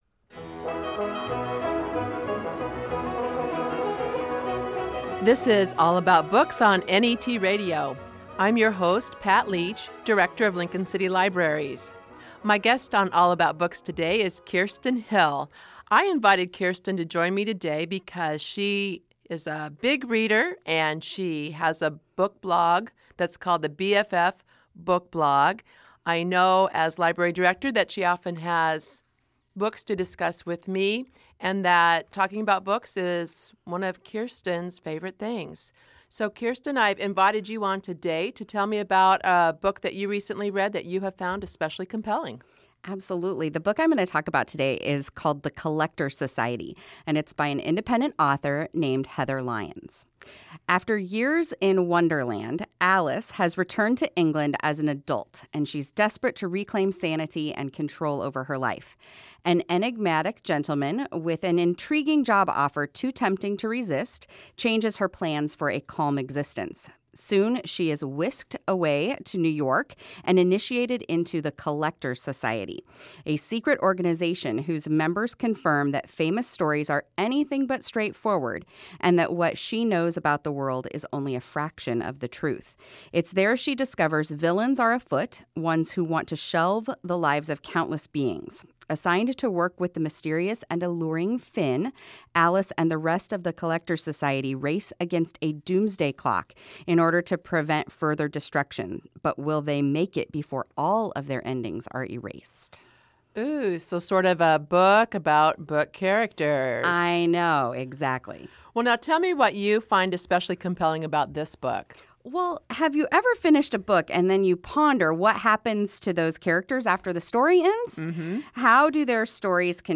We also chatted a little bit about reading and blogging. Today, my little episode of All About Books airs on NET Radio and the podcast is available online.